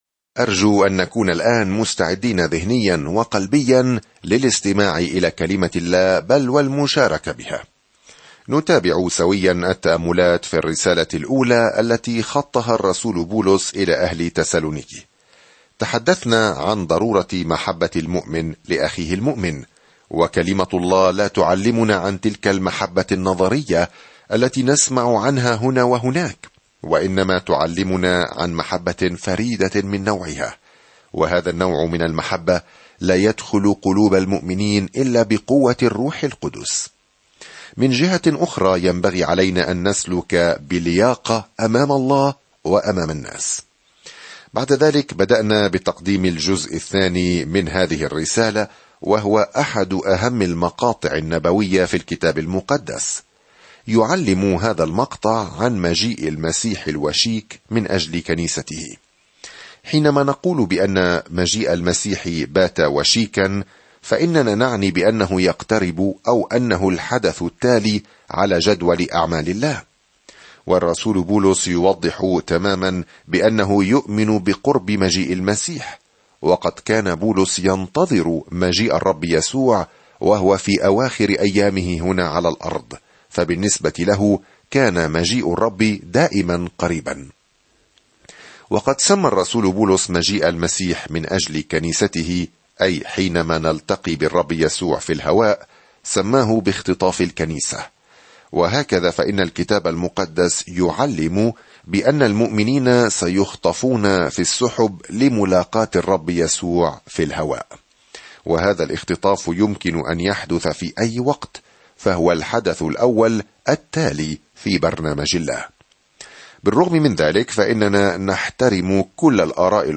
There is an audio attachment for this devotional.
سافر يوميًا عبر رسالة تسالونيكي الأولى وأنت تستمع إلى الدراسة الصوتية وتقرأ آيات مختارة من كلمة الله.